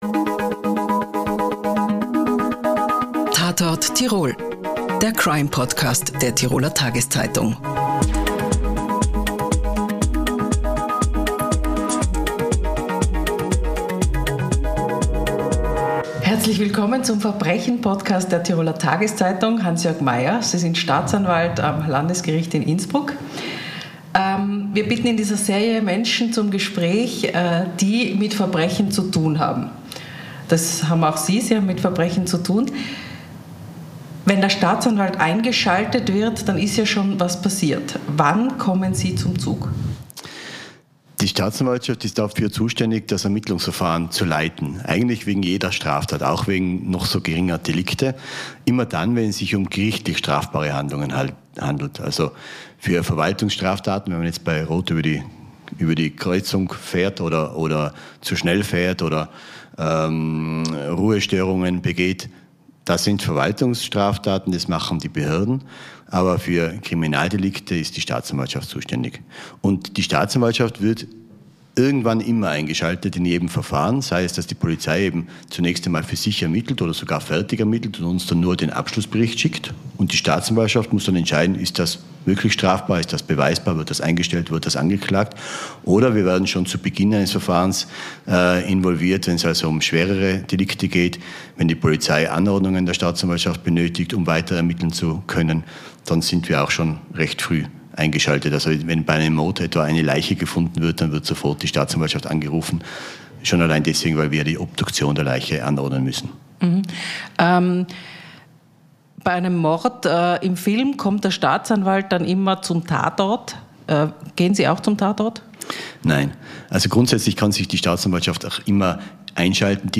Tatort Tirol ist der Crime-Podcast der Tiroler Tageszeitung. In dieser fünfteiligen Serie bitten wir Menschen zum Gespräch, die beruflich mit Verbrechen zu tun haben.